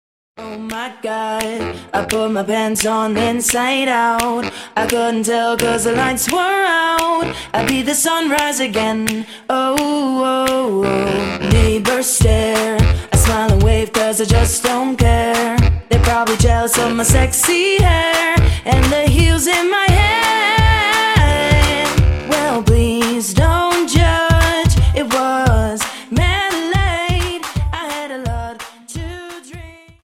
Dance: Jive 43 Song